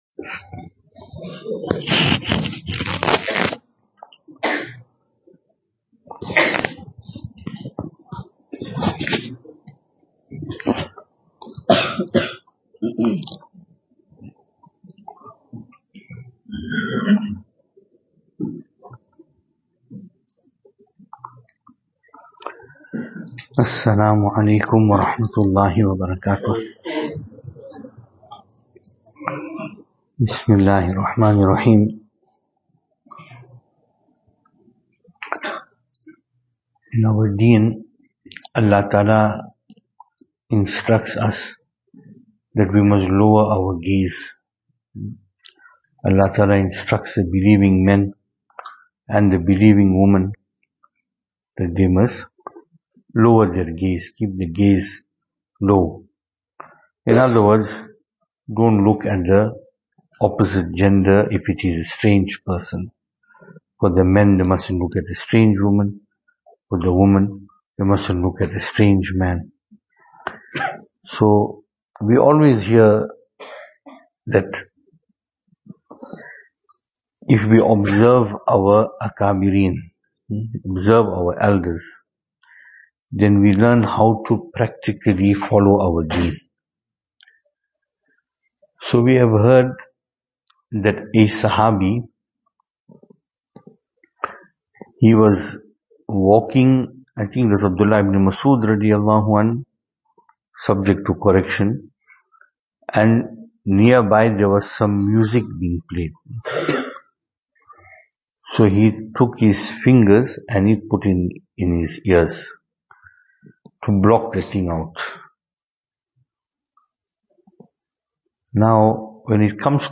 Venue: Albert Falls , Madressa Isha'atul Haq
Service Type: Islahi Majlis